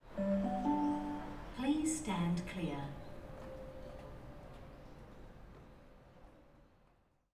please_stand_clear.wav